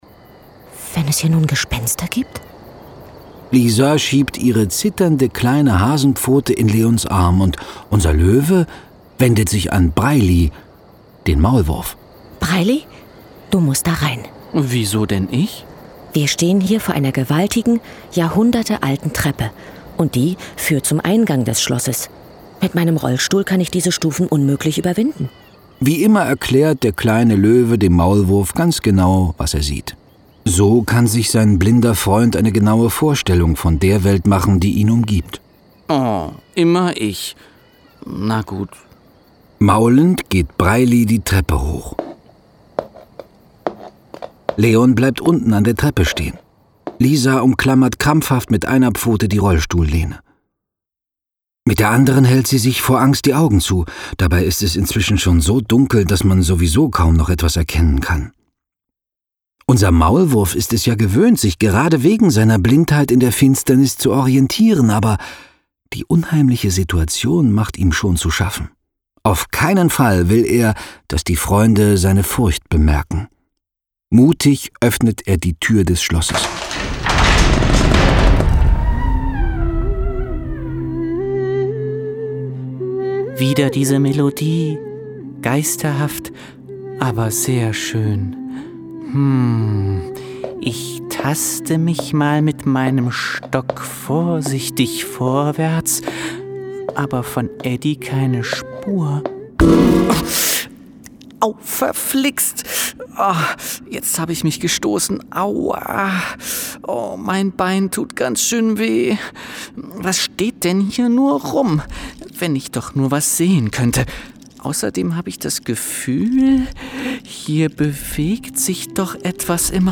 Hörspiel „Der kleine Löwe und seine Freunde entdecken Sachsen“
Kleiner-Löwe-Hörspiel-ausschnitt.mp3